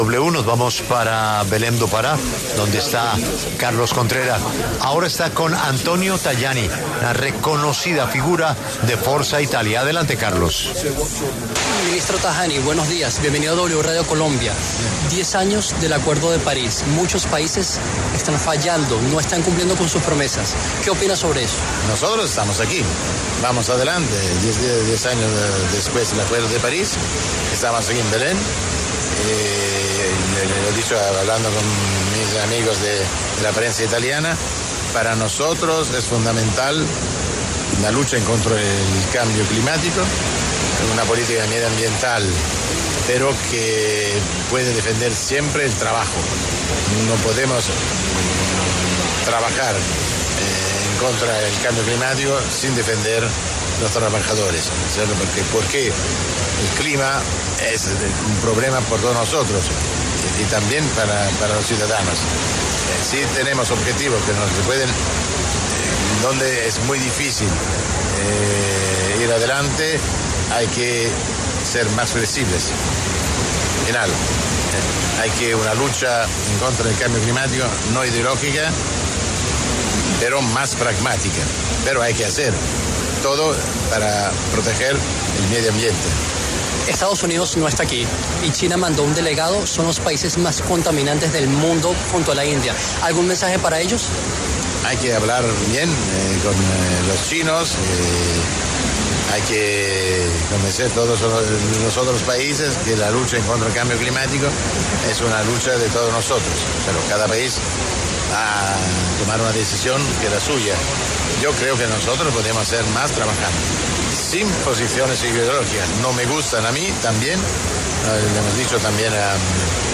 El político italiano perteneciente al partido Forza Italia y al Partido Popular Europeo, Antonio Tajani, también actual vicepresidente del Consejo de Ministros de la República Italiana, conversó con La W desde la COP30 acerca de la lucha contra el cambio climático.